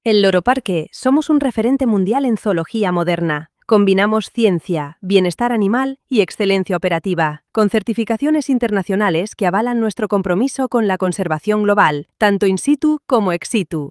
Muestra de voces con IA
Voces femeninas
Cálida, enérgica y segura